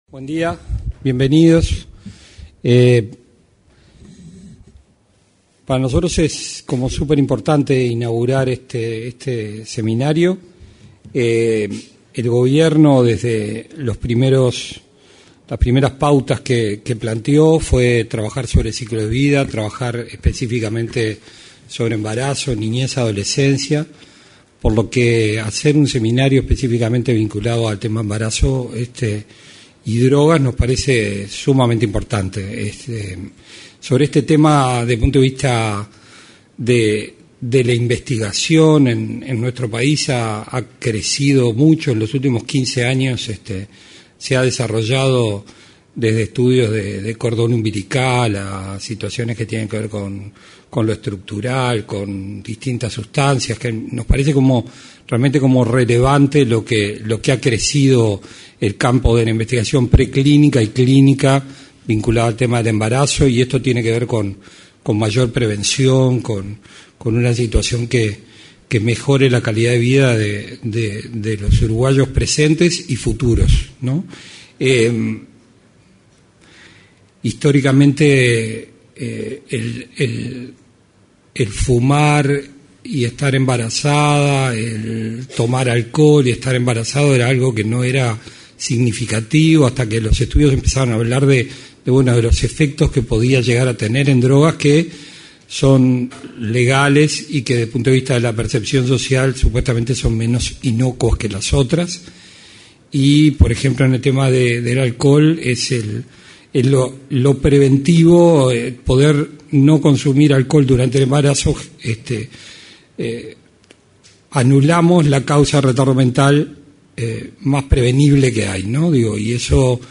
Seminario Embarazo y Uso de Drogas, Desafíos para la Atención Integral 14/11/2025 Compartir Facebook X Copiar enlace WhatsApp LinkedIn En el salón de actos de la Torre Ejecutiva, se realizó la apertura del seminario Embarazo y Uso de Drogas, Desafíos para la Atención Integral. En la oportunidad, se expresaron el titular de la Secretaría Nacional de Drogas, Gabriel Rossi, y la gerenta del programa Uruguay Crece Contigo, del Ministerio de Desarrollo Social, Virginia Cardozo.